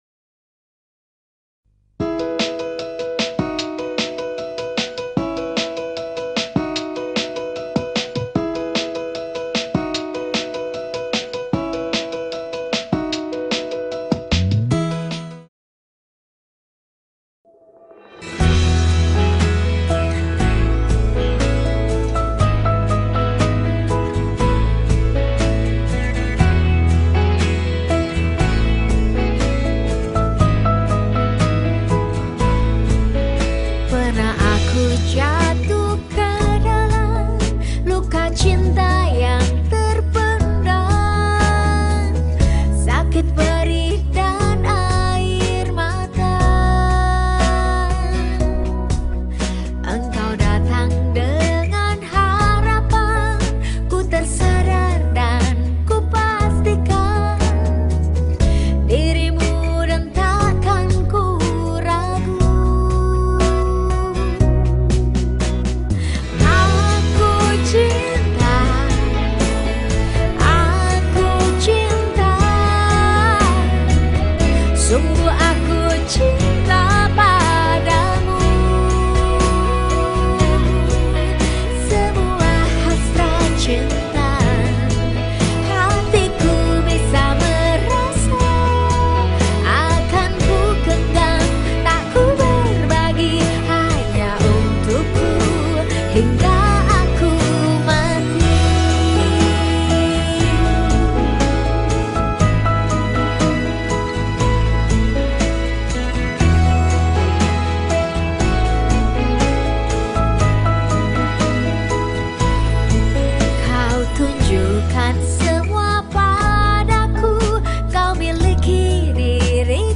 penyanyi pop